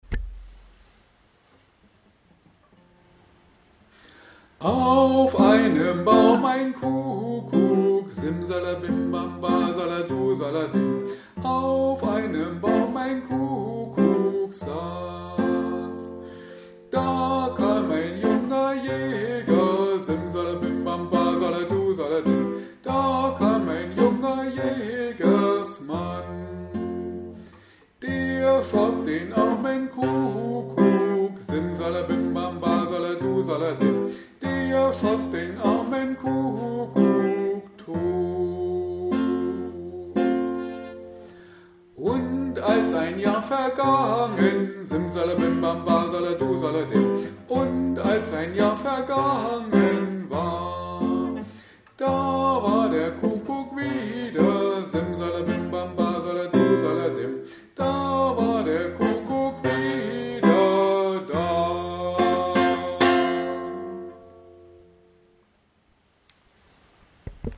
Da auch wir zur Zeit in vielen Seniorenheimen nicht arbeiten können und folglich auch keine Gruppen stattfinden, haben wir Ihnen ein kleines Liederbuch mit dazugehöriger Begleitung zusammengestellt.